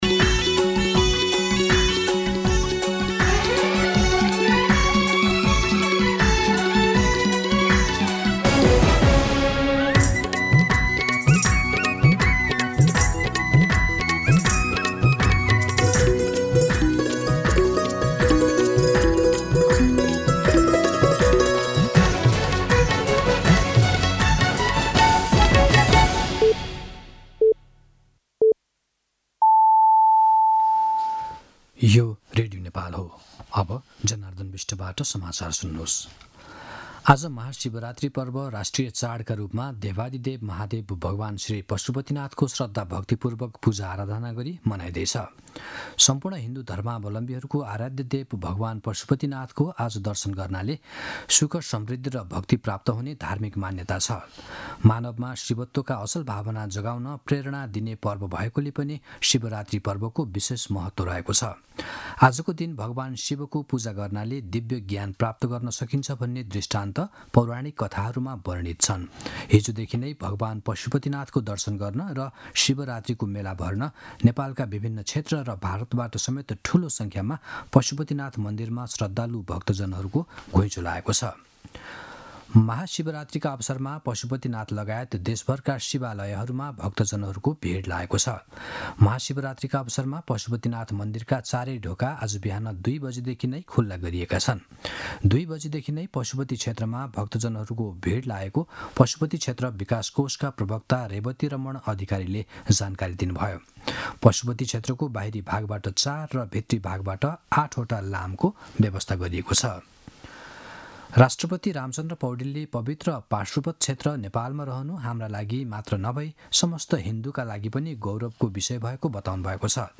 दिउँसो १ बजेको नेपाली समाचार : १५ फागुन , २०८१